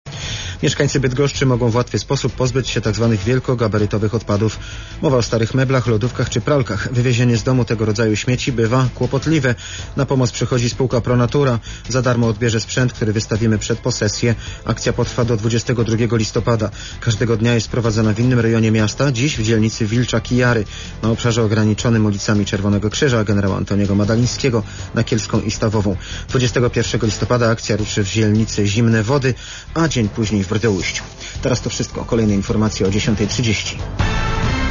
wiadomosci-polskiego-radia-pik.wav